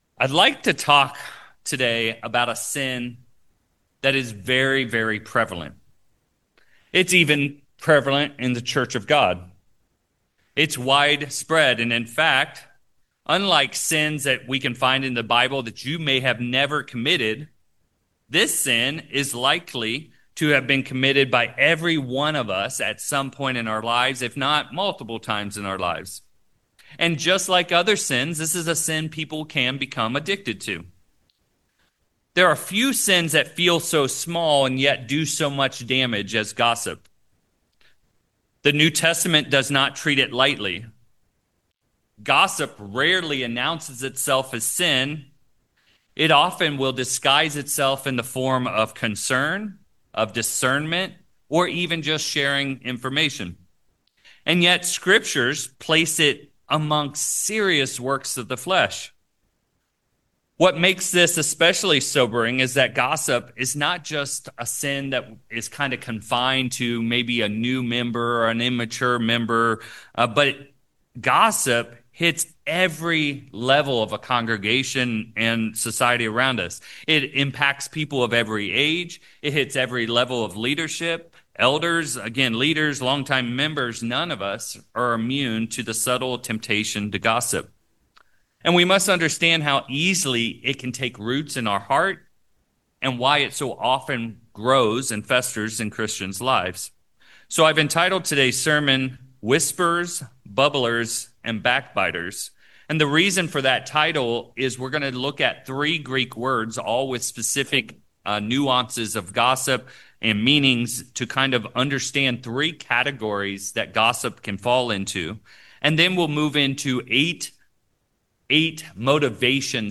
2/28/26 This sermon looks at three New Testament words associated with gossip.